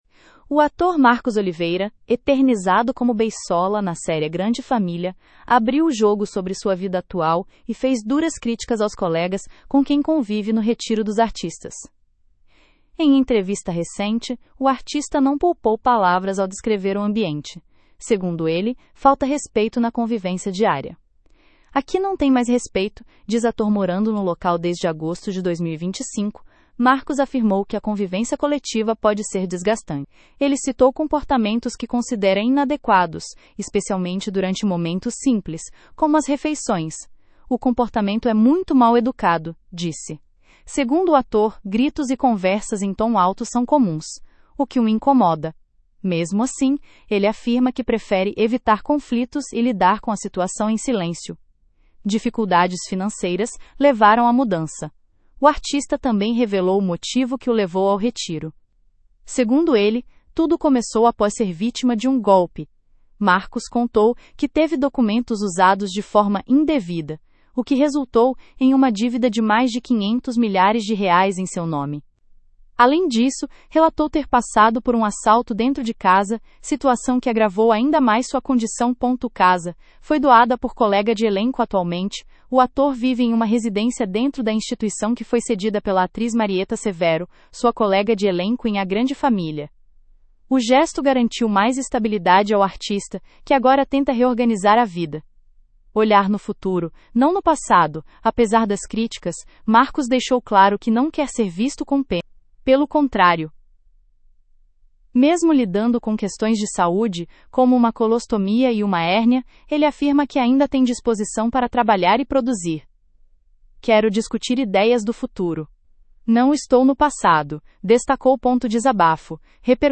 Em entrevista recente, o artista não poupou palavras ao descrever o ambiente: segundo ele, falta respeito na convivência diária.